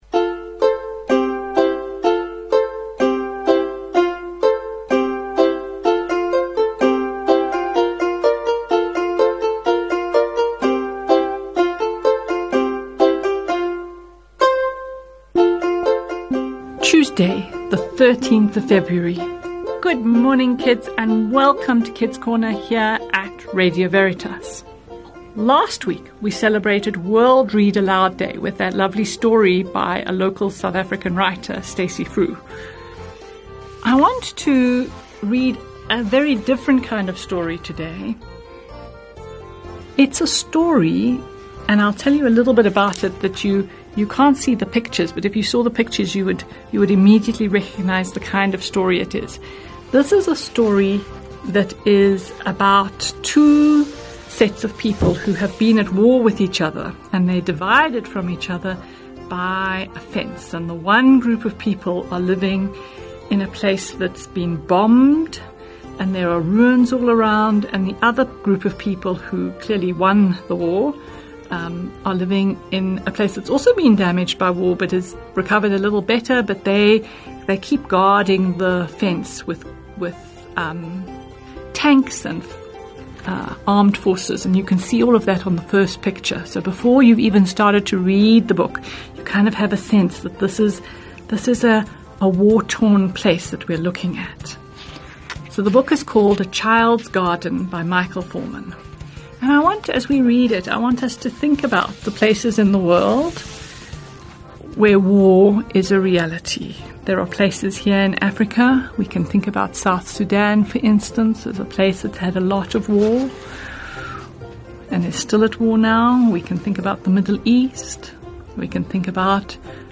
Reading 'A Child's Garden' by Michael Foreman